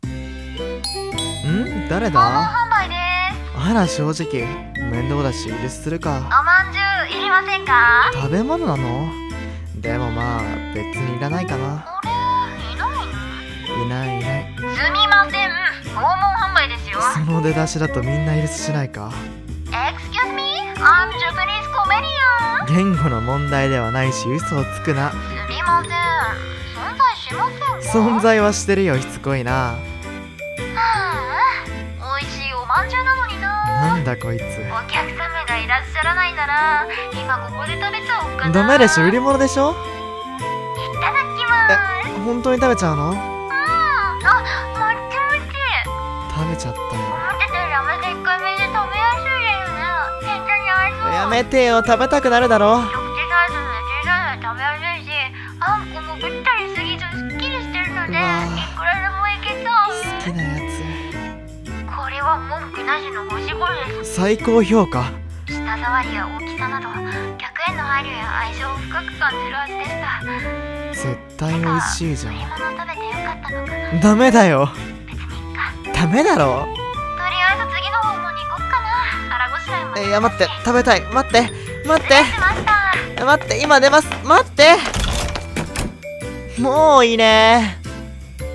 【ギャグ声劇】多分良い性格の訪問販売